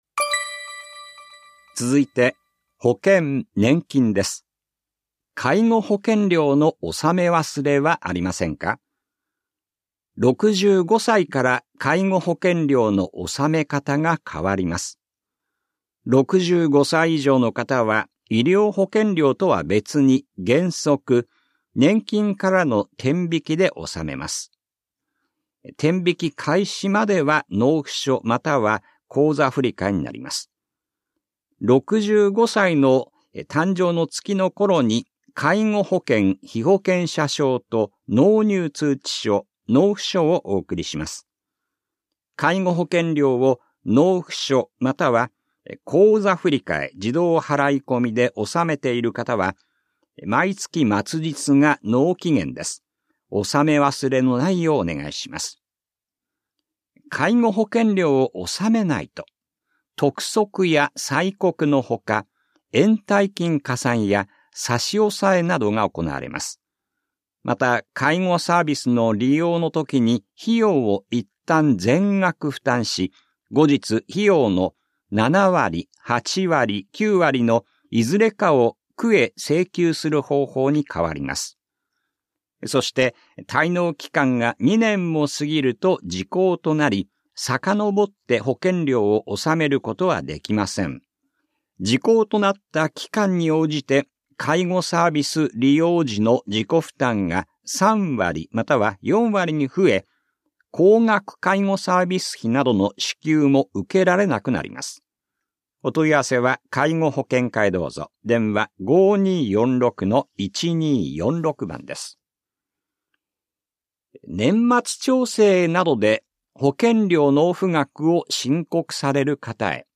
広報「たいとう」令和5年11月20日号の音声読み上げデータです。